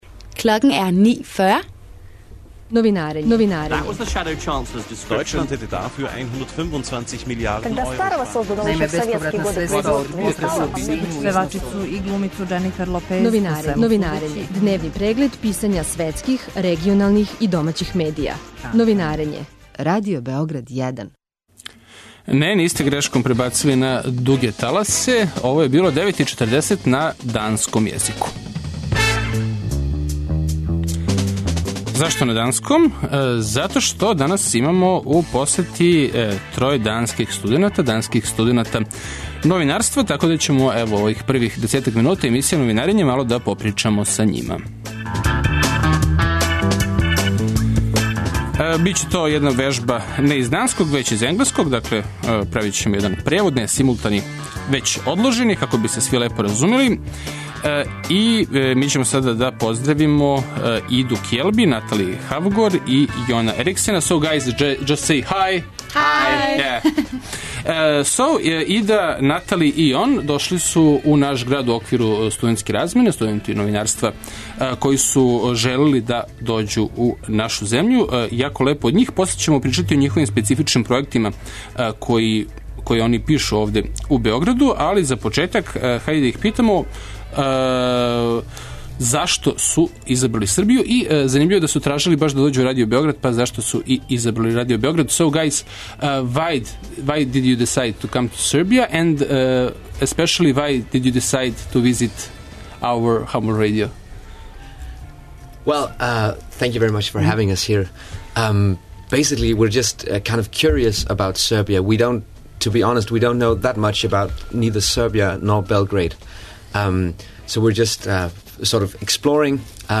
Они су нам испричали како медији у Данској прате дешавања на нашим просторима, као и на шта су одлучили да се фокусирају у својим текстовима о Београду и Србији.